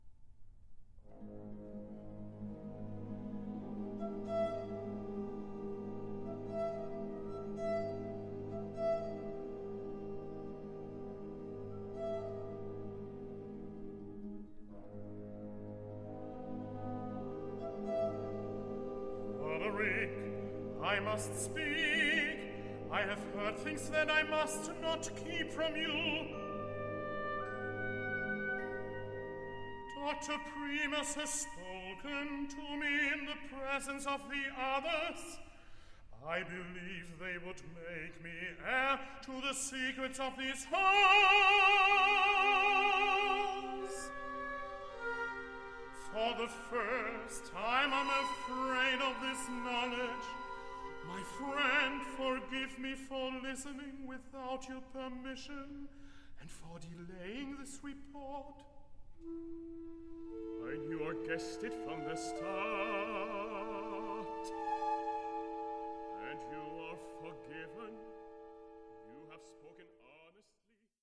Opera in One Act